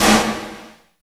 50 VERB SN-L.wav